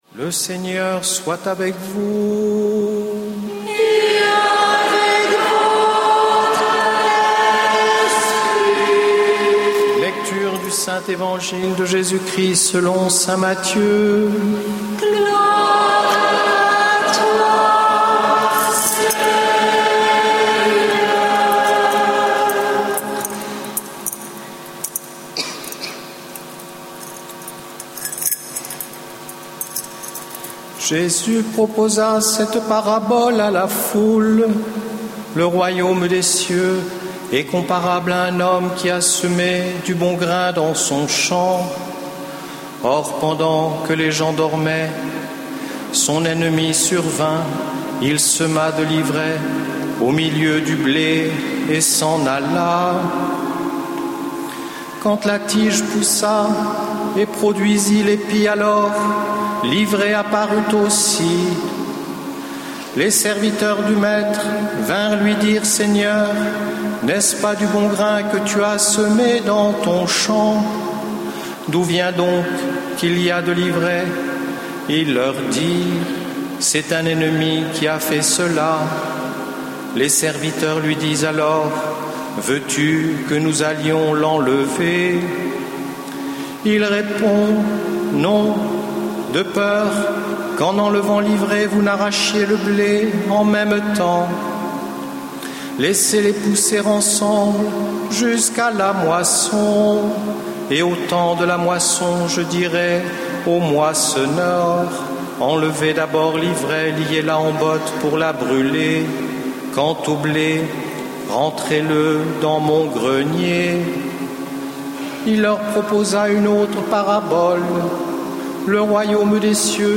Enregistré en 2011 (Session de la Communauté des Béatitudes - Lourdes 13 au 17 juillet 2011)
Format :MP3 64Kbps Mono